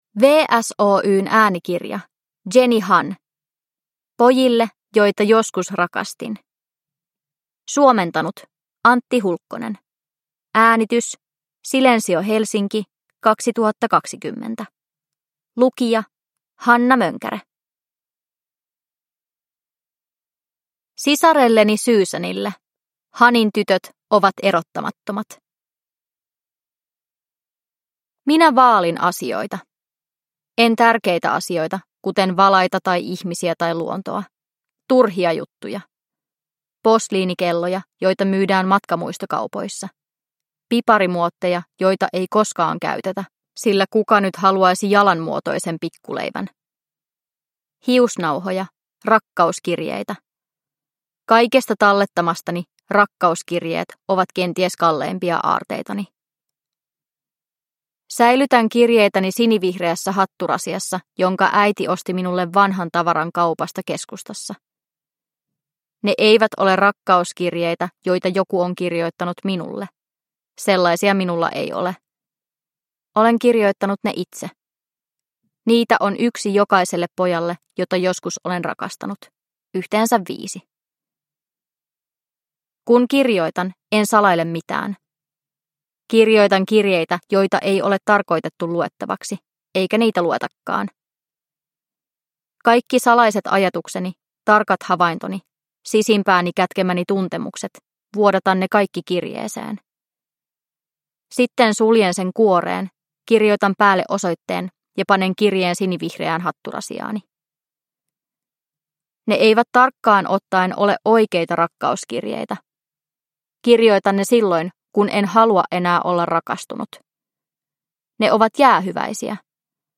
Pojille, joita joskus rakastin – Ljudbok – Laddas ner